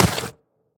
biter-roar-mid-1.ogg